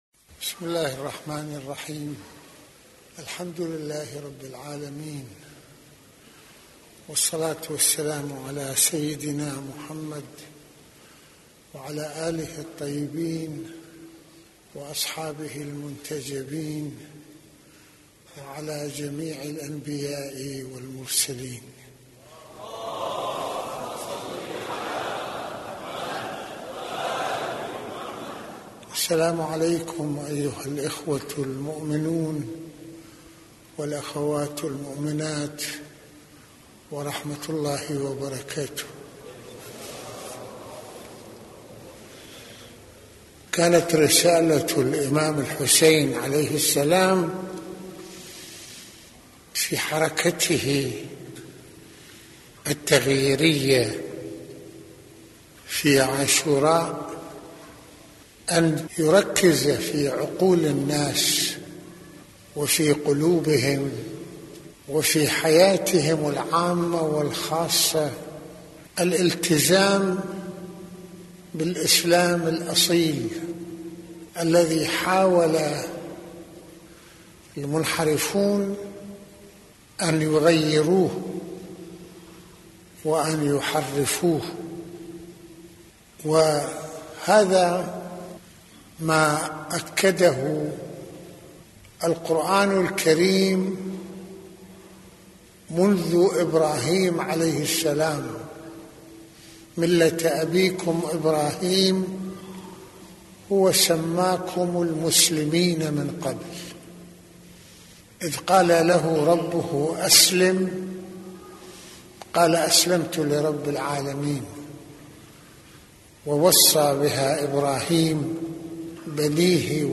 - يتحدث سماحة المرجع السيد محمد حسين فضل الله (رض) في هذه المحاضرة العاشورائية عن حركة الإمام الحسين(ع) التغييرية التي توخّت إعادة توجيه العقول والمشاعر نحو الإلتزام بروح الغسلام الأصيل في مواجهة الانحراف والمنحرفين ..وتلك هي رسالة الأنبياء تأكيد الإسلام في الحياة بدءا بإبراهيم (ع) على قاعدة التوحيد والتسليم لله...